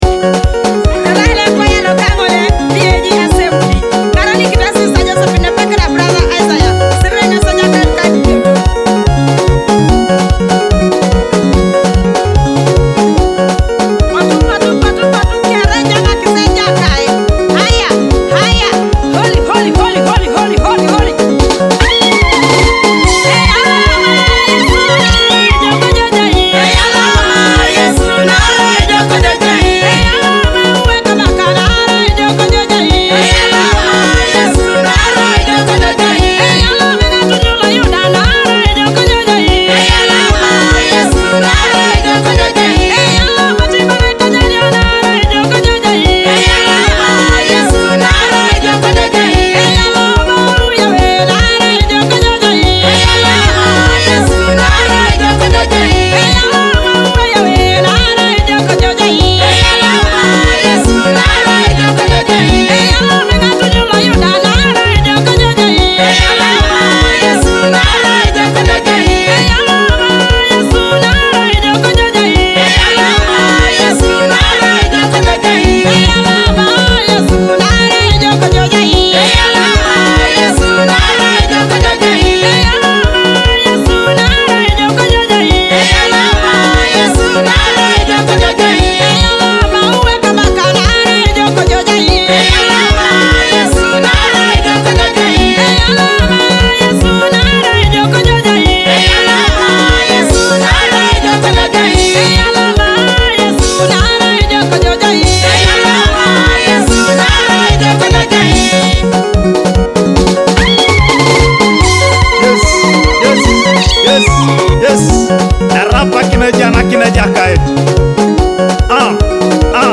With soul-stirring harmonies and heartfelt worship